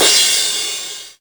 CRASH07   -L.wav